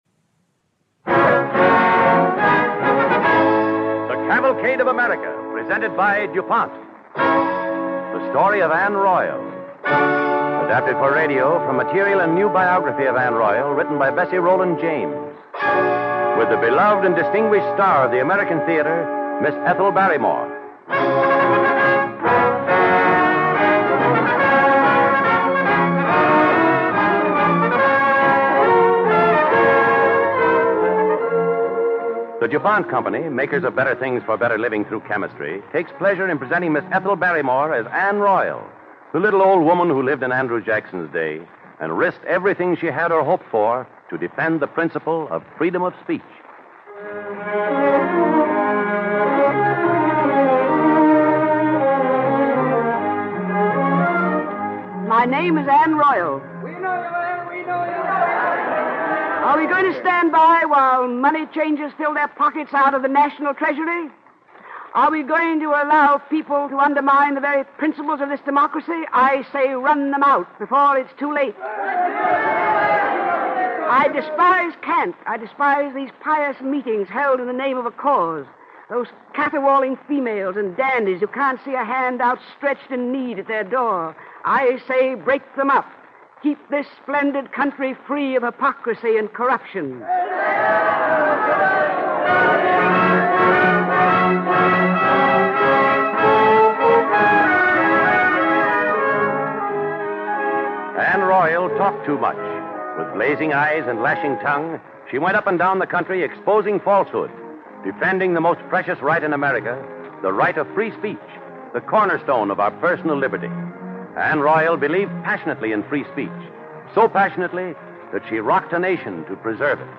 Cavalcade of America Radio Program
Anne Royall, starring Ethel Barrymore